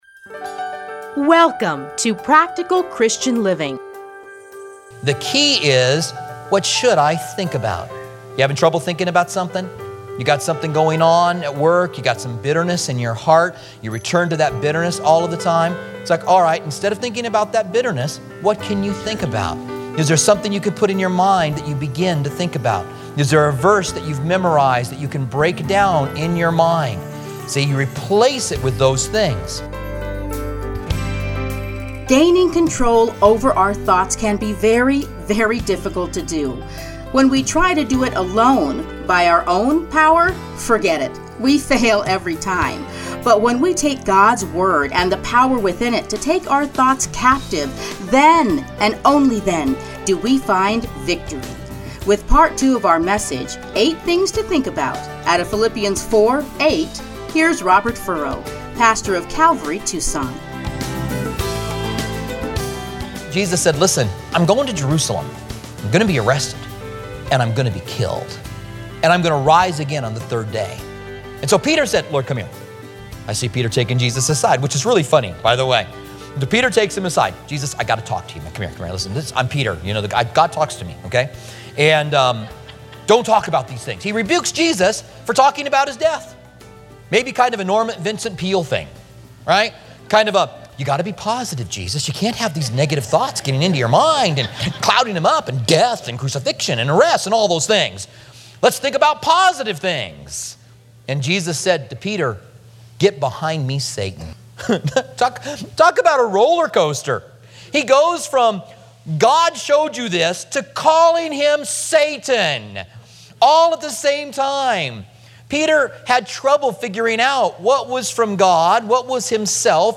teachings are edited into 30-minute radio programs